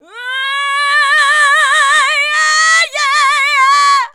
UUUH 1.wav